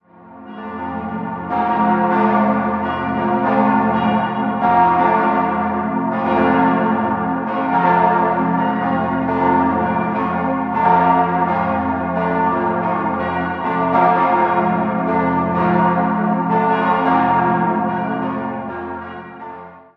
Sie wurde im Jahr 1904 eingeweiht und besitzt eines der tontiefsten Geläute der Schweiz. Innenansicht wird noch ergänzt. 4-stimmiges F-Dur-Geläute: f°-a°-c'-f' Die Glocken wurden 1904 von der Gießerei Rüetschi in Aarau gegossen.